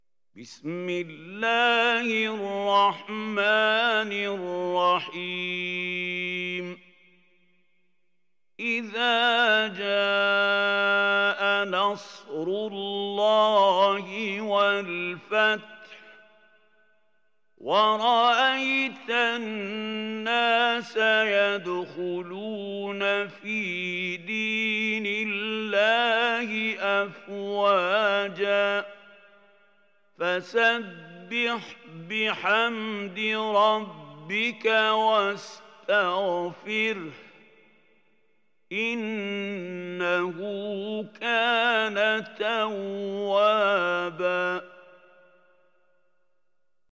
Nasr Suresi mp3 İndir Mahmoud Khalil Al Hussary (Riwayat Hafs)